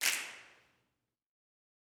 SNAPS 32.wav